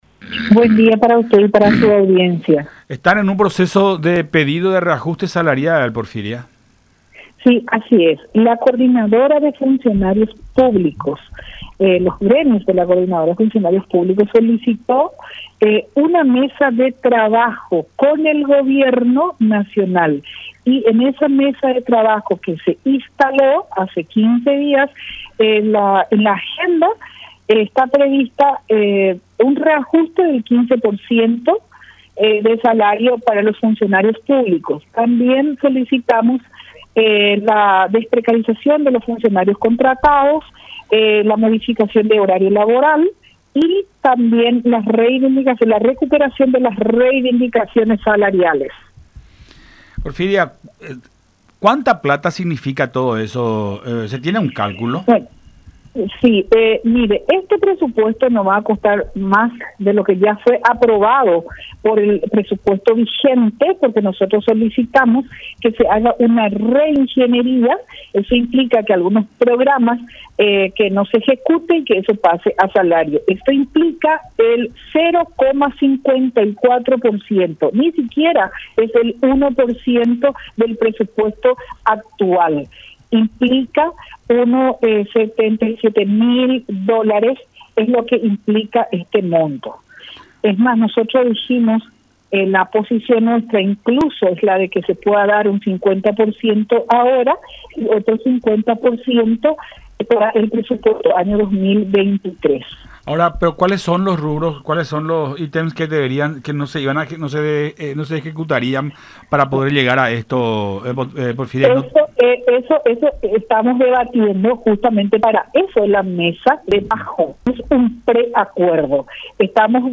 en diálogo con Todas Las Voces por La Unión.